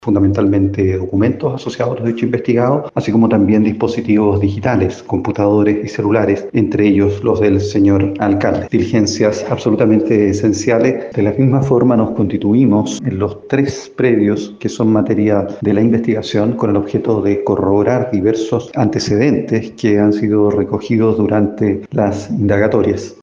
El Fiscal Jefe de la Unidad Regional Anticorrupción, Claudio Rebeco, detalló el procedimiento para obtener las evidencias de la investigación.